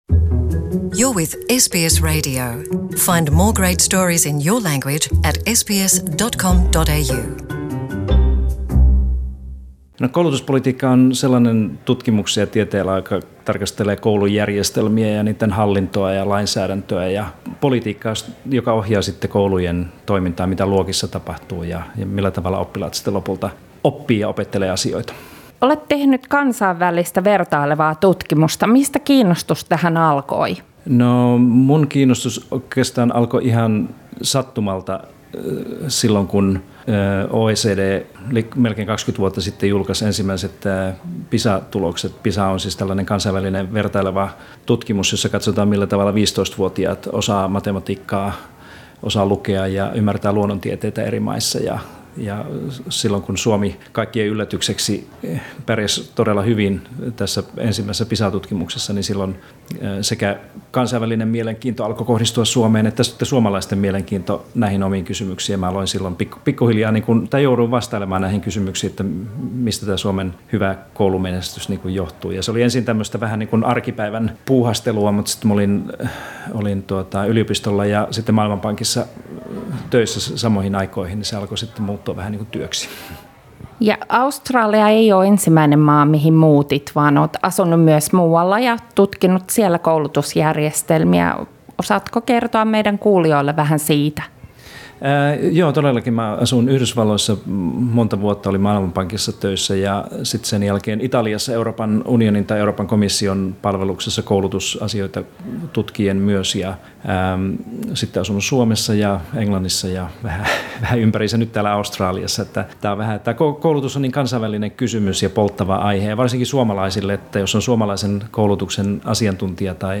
Haastateltavana koulutuspolitiikan professori Pasi Sahlberg